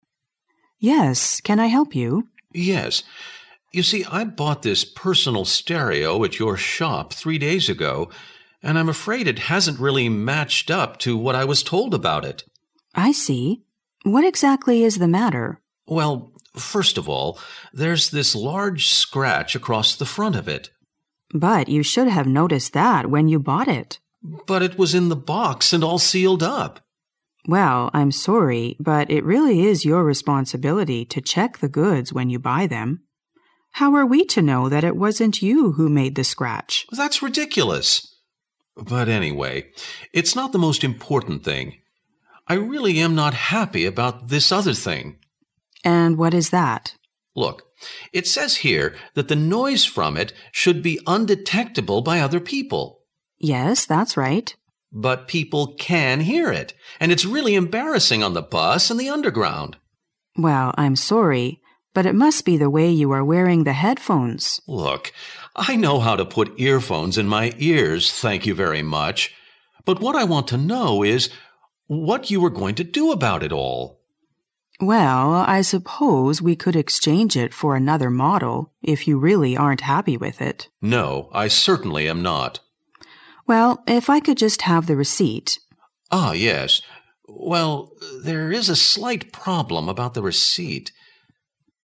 2009年英语专业四级:对话2